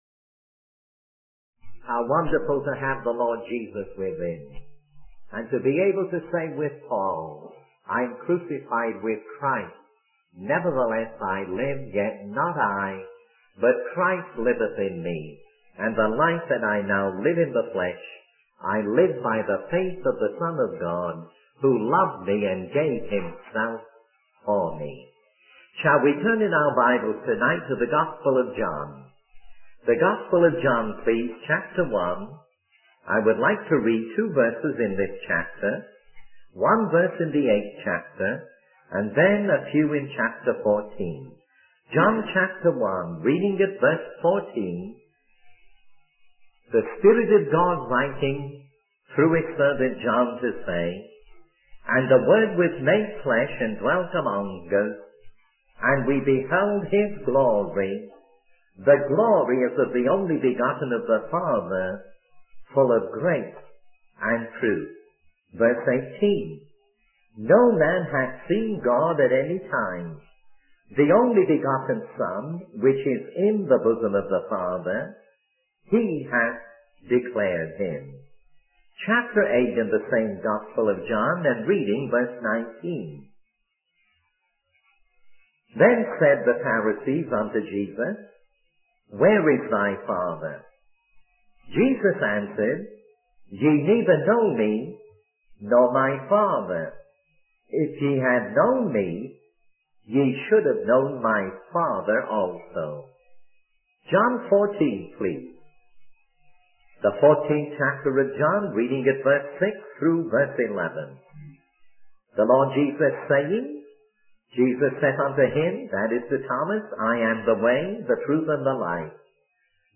In this sermon, the preacher emphasizes the love of God towards humanity.